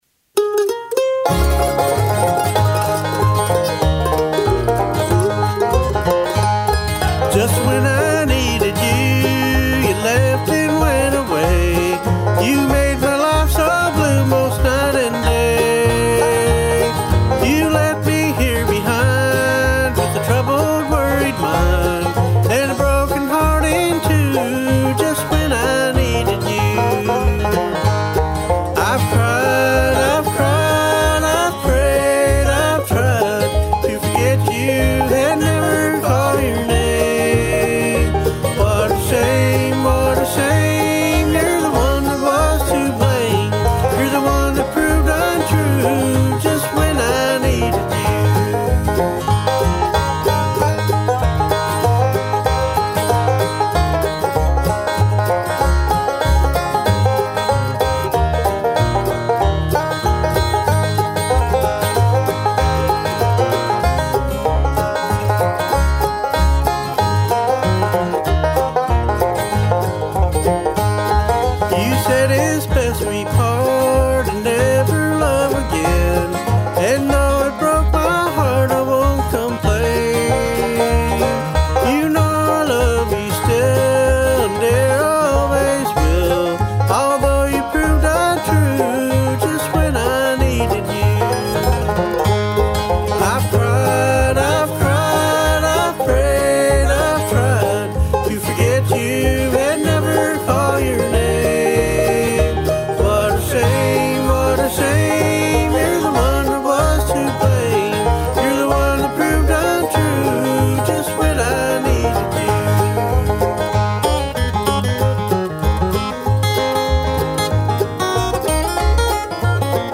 Bluegrass song - "Just When I Needed You".. need opinion on outcome
This song was done using Pro Tools' standard plug-ins.
I played all the instruments except bass, and sang all the vocals.